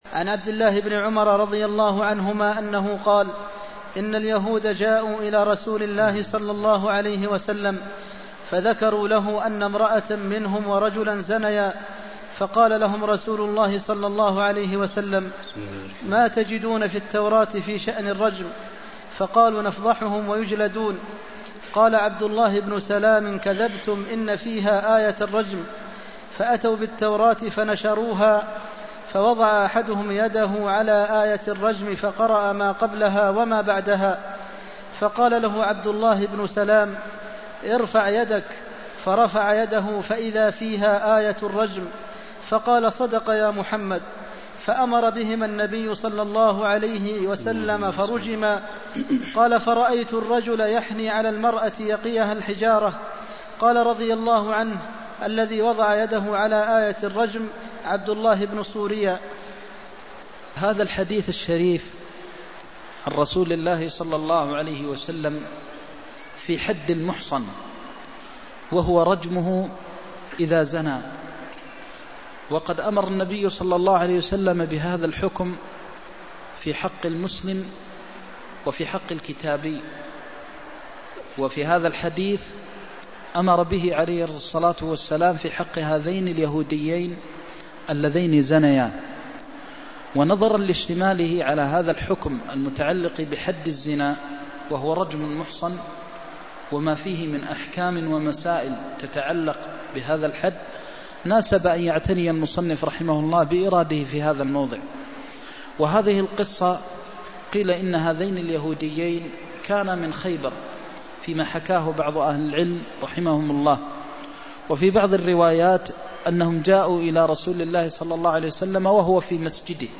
المكان: المسجد النبوي الشيخ: فضيلة الشيخ د. محمد بن محمد المختار فضيلة الشيخ د. محمد بن محمد المختار ماتجدون في التوراة في شأن الرجم (332)